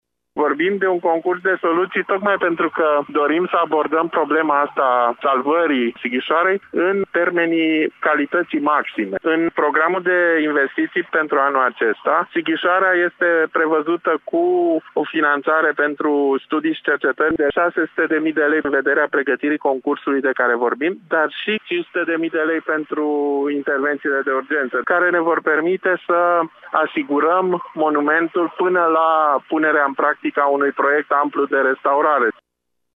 Directorul Institutului Național al Patrimoniului Ștefan Bâlici a explicat pentru Radio Tg.Mureș că sunt prevăzuți bani atât pentru pregătirea unui concurs de soluții de restaurare totală a fortificațiilor, cât și pentru reparații curente la porțiunile de zid vulnerabile: